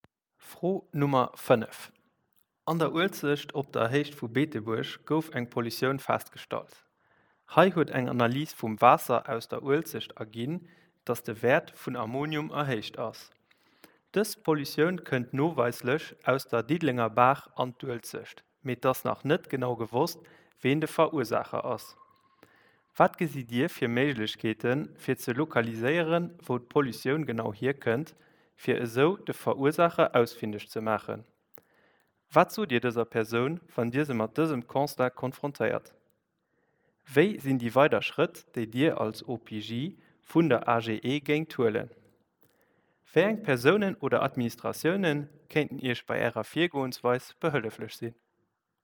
Interview_OPJ_Posten.mp3